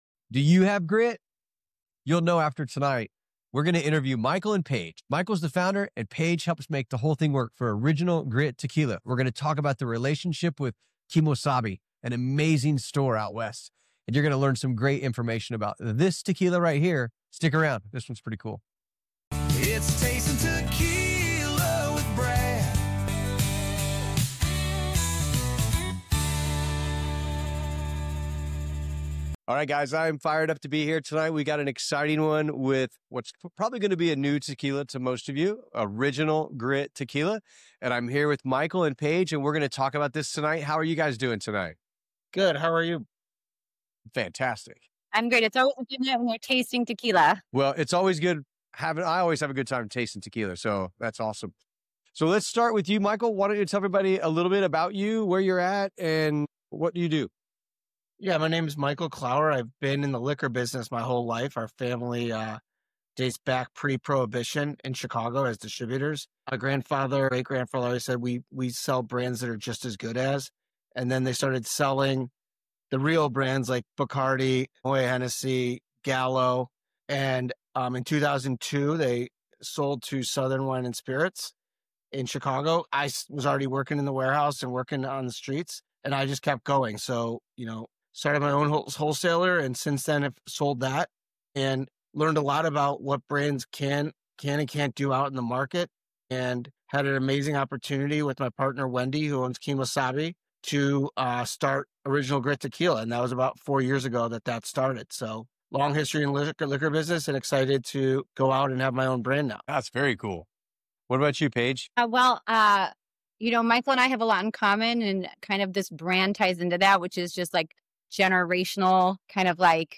In this exclusive interview, we dive into the Original Grit Tequila story, born at the iconic Kemo Sabe store in Aspen. The founders share how this tequila was created, the distillation process, flavor profiles, and what makes Original Grit stand out in the tequila industry.